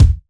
Chart Kick 01.wav